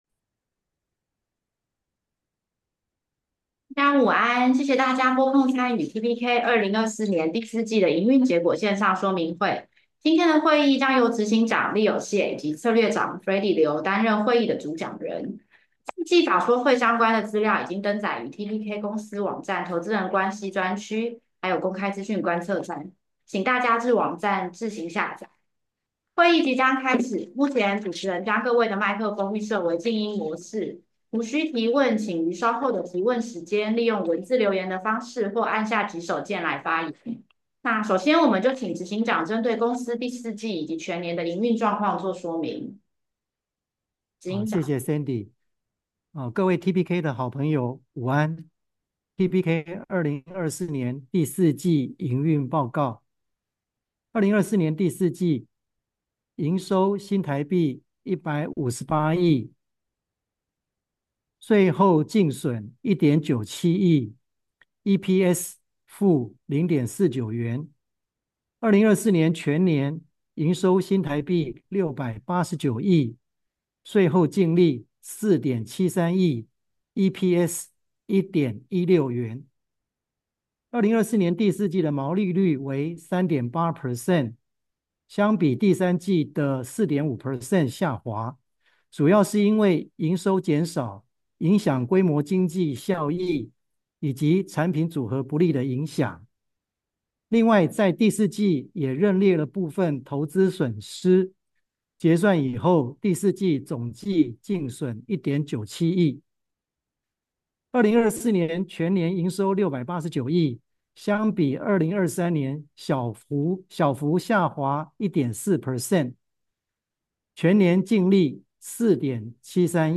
Earnings Release